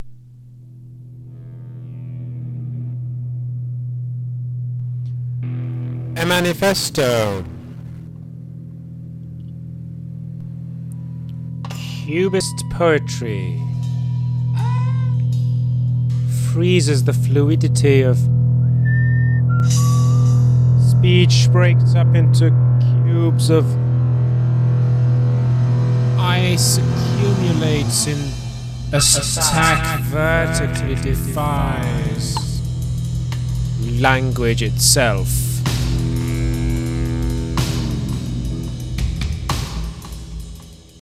برای شنیدن شعر با صدای شاعر